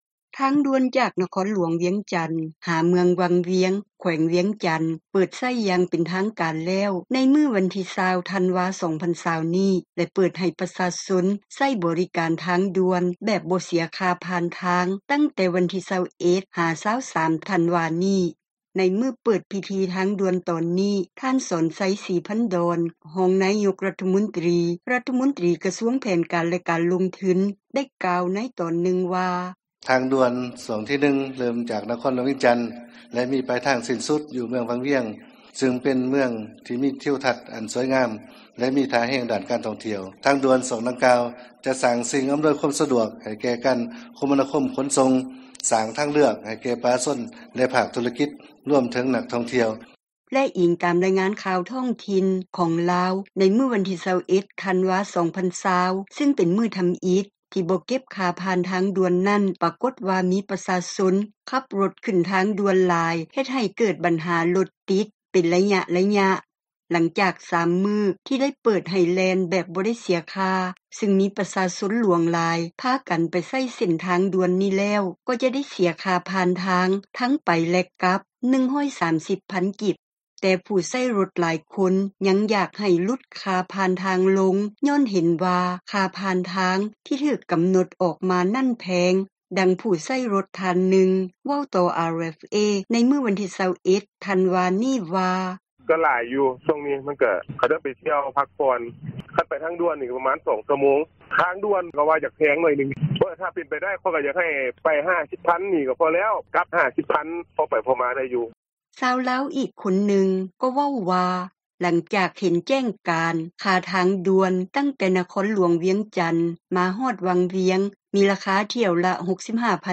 ຊາວບ້ານໜັກໃຈຄ່າທາງດ່ວນ ວຽງຈັນ-ວັງວຽງ — ຂ່າວລາວ ວິທຍຸເອເຊັຽເສຣີ ພາສາລາວ
ຫຼັງຈາກ 3 ມື້ ທີ່ເປີດໃຫ້ແລ່ນ ແບບບໍ່ໄດ້ເສັຍຄ່າ ຊຶ່ງມີປະຊາຊົນຫຼວງຫຼາຍ ພາກັນໄປໃຊ້ເສັ້ນທາງດ່ວນນີ້ແລ້ວ ກໍຈະໄດ້ເສັຽຄ່າທາງ ທັງໄປແລະກັບ 130,000 ກີບ. ແຕ່ຜູ້ໃຊ້ຣົດ ຫຼາຍຄົນ ຍັງຢາກໃຫ້ຫຼຸດ ຄ່າຜ່ານທາງລົງ ຍ້ອນເຫັນວ່າຄ່າຜ່ານທາງ ທີ່ຖືກກໍານົດ ອອກມານັ້ນ ແພງ, ດັ່ງຜູ້ໃຊ້ຣົດທ່ານນຶ່ງ ເວົ້າຕໍ່ RFA ໃນມື້ວັນທີ 21 ທັນວານີ້ວ່າ: